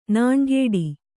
♪ nāṇgēḍi